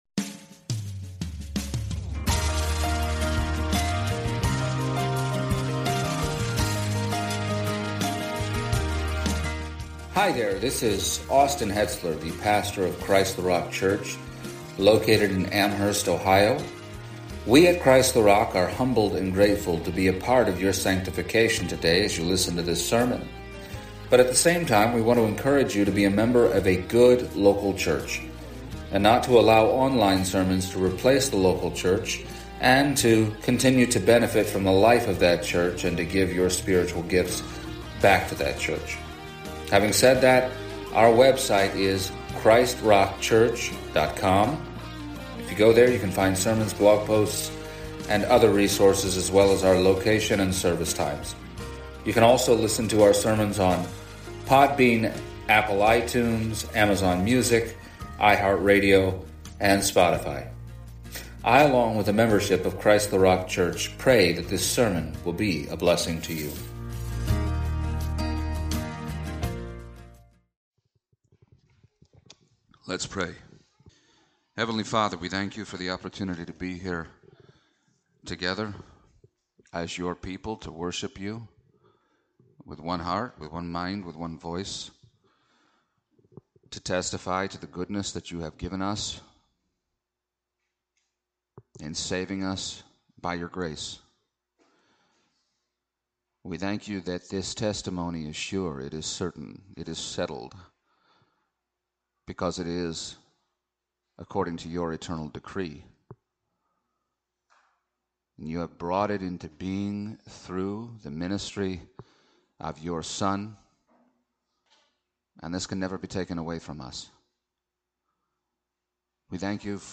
Passage: Hebrews 2:1-4 Service Type: Sunday Morning